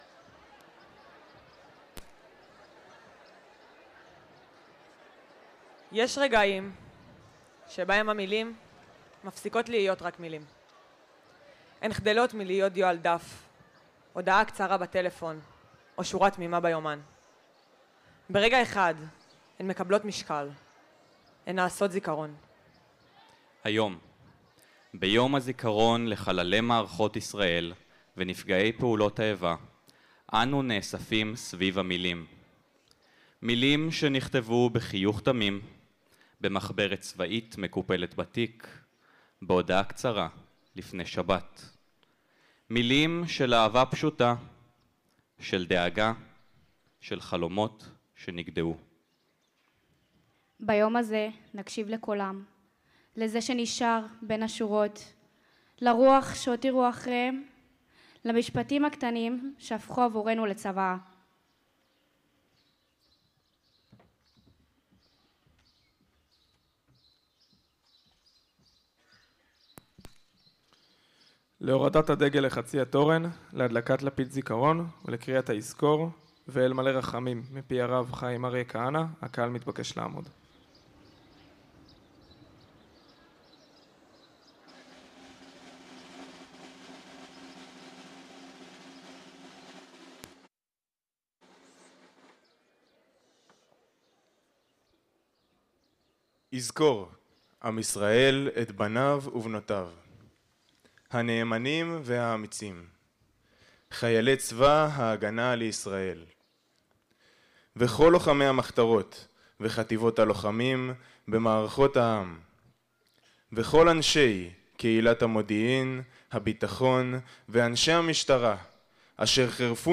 הקלטת הטקס המרכזי, קריית החינוך גינסבורג יבנה, תשפ"ו